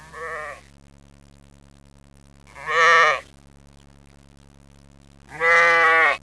BAAAAAA!
SHEEP02.wav